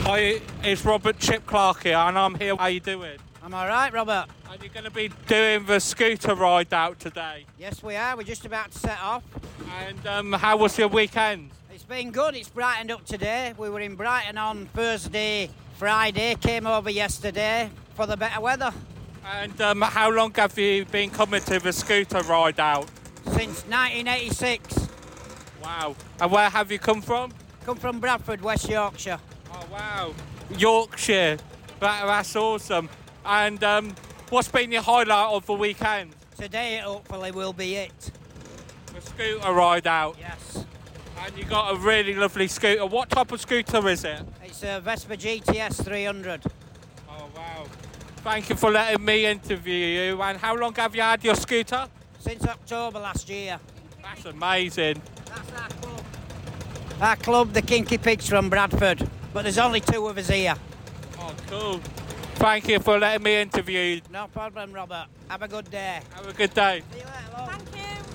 Scooters Ride Out Interview 2024